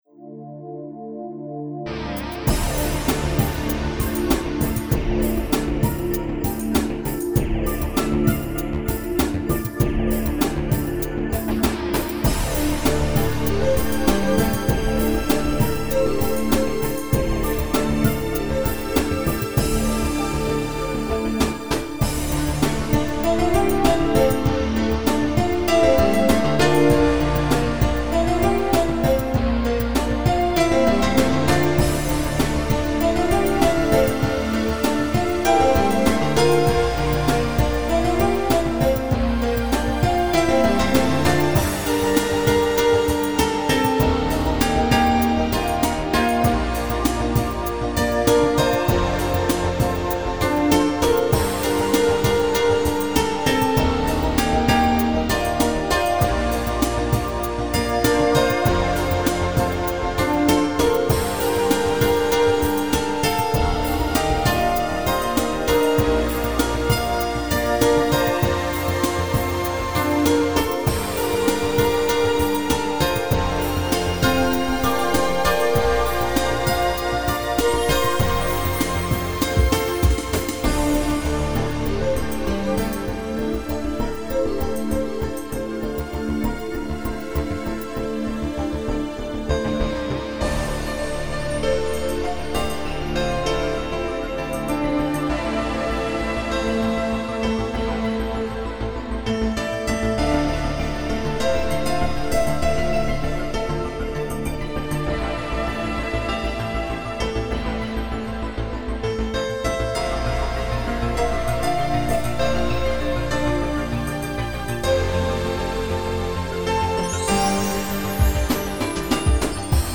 Synthpop 1998